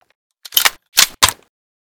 l85_unjam.ogg